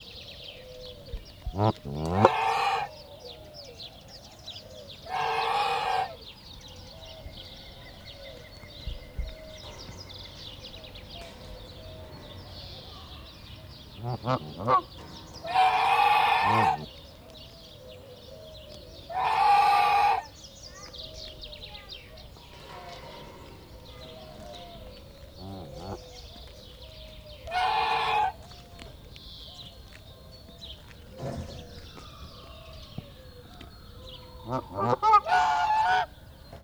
ovelekezdafelvetel_majdkanadailud_marantzpuska00.36.WAV